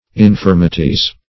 infirmities - definition of infirmities - synonyms, pronunciation, spelling from Free Dictionary